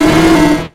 Cri de Maraiste dans Pokémon X et Y.